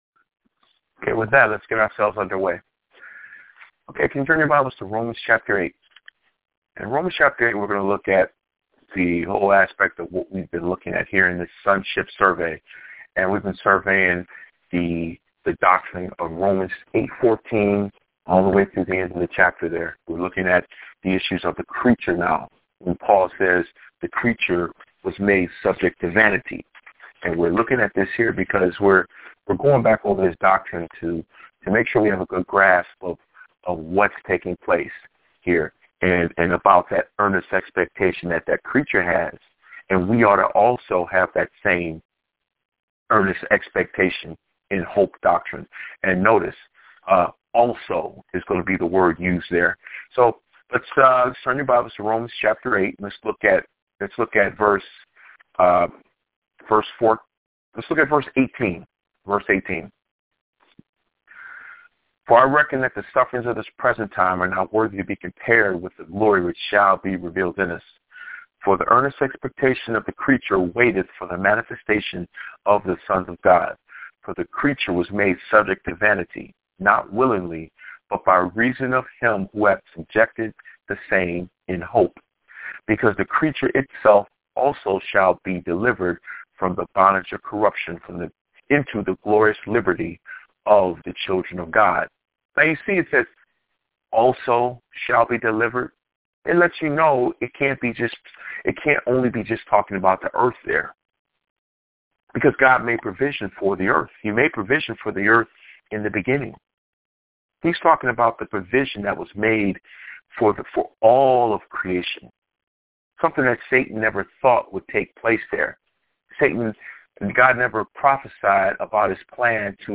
Weekly Phone Bible Studies